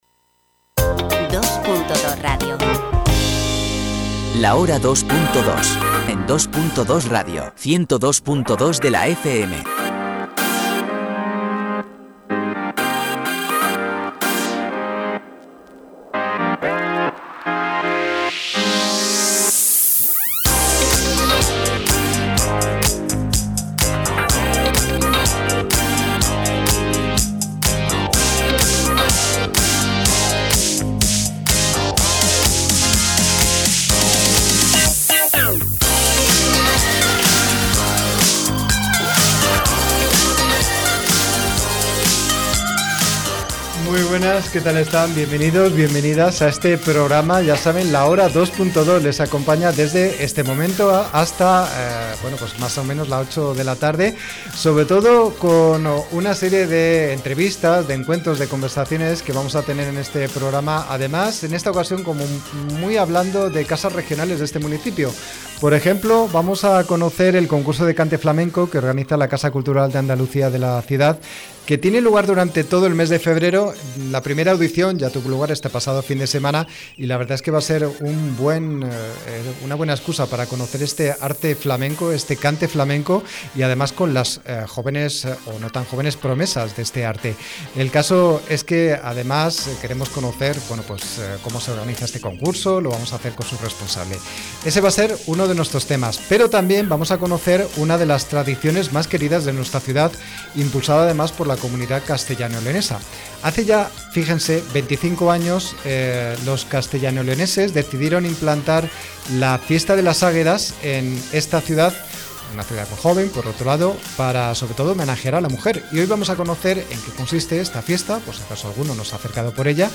Hoy 4 de febrero de 2026 es la décima entrega de la quinta temporada del magazine La Hora 2.2 de Dos.Dos Radio Formación. Les acompañaremos durante una hora con contenidos que han desarrollado los integrantes del taller de radio que hacemos en Factoría Cultural.